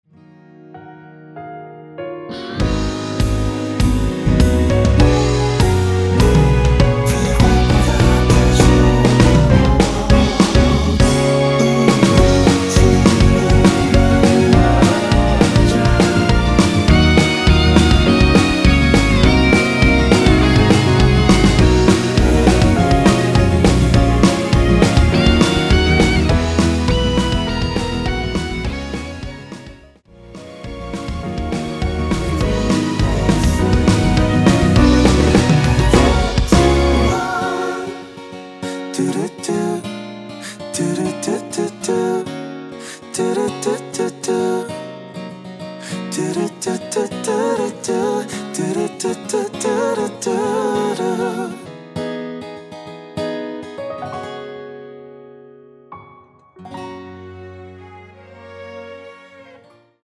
원키 코러스 포함된 MR입니다.
Gb
앞부분30초, 뒷부분30초씩 편집해서 올려 드리고 있습니다.
중간에 음이 끈어지고 다시 나오는 이유는